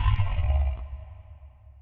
synthFX.wav